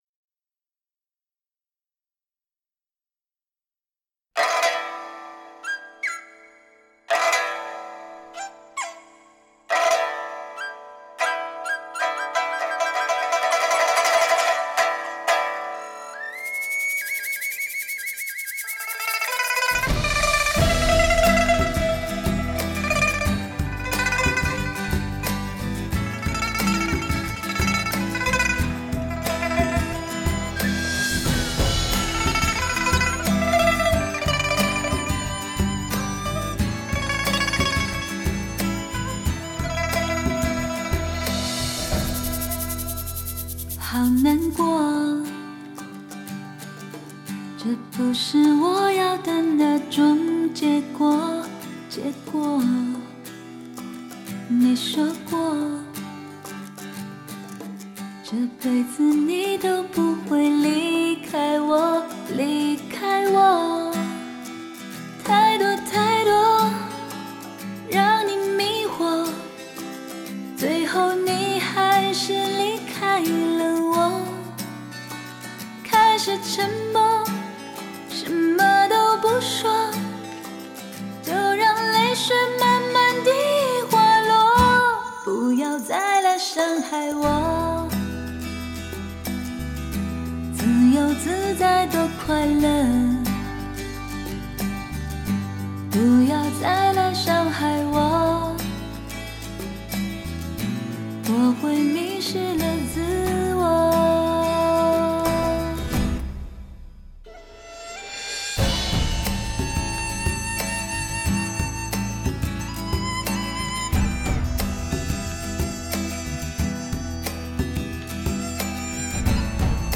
爱的声调，情的音质。
把声音塑造得趋于完美的DTS技术，
是一种空前的逼真演绎，细心打造现场聆听感，
婉转流莹的旋律弹奏加上多愁善感的人声绝唱，